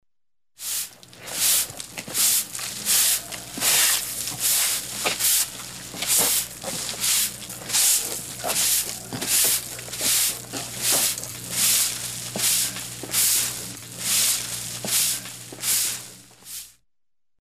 Звуки метлы
Шуршание метлы по асфальту